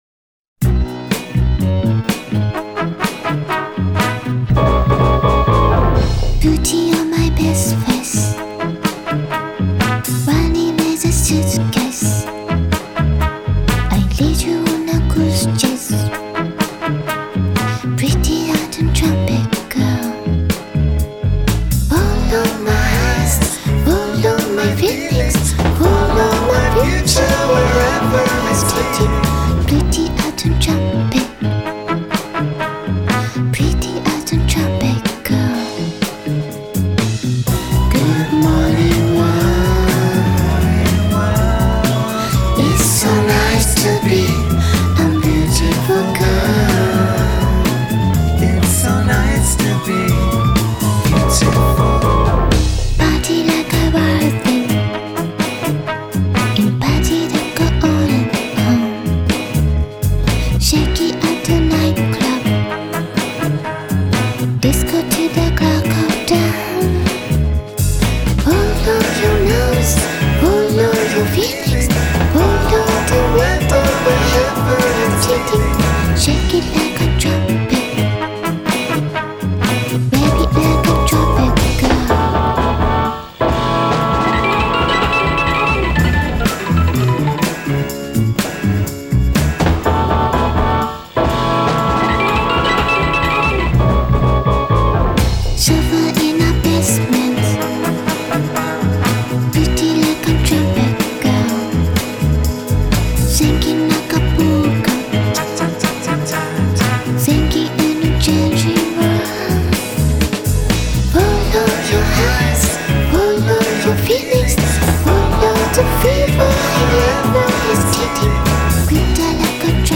Incomprehensible Japanese dance pop, GO!!!!